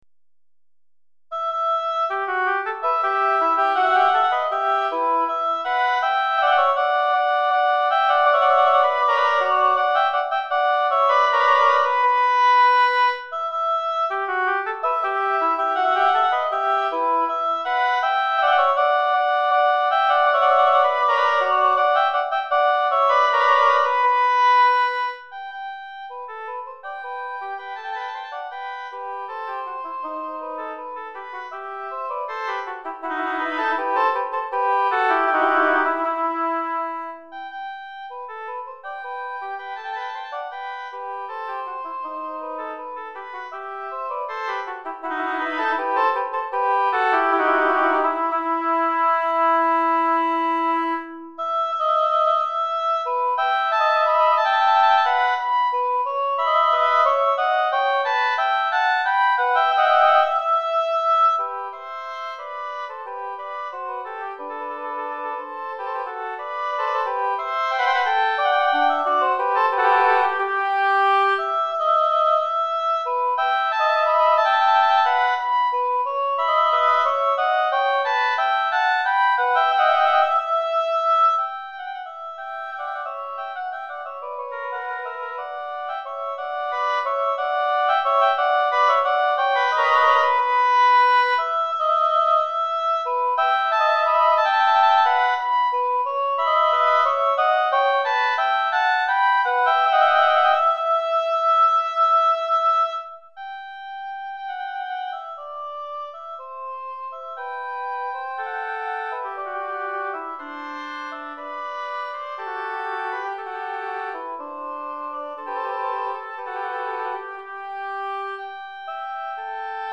Répertoire pour Hautbois - 2 Hautbois, en vente chez LMI - Suivi d'expédition, satisfait ou remboursé, catalogue de 300 000 partitions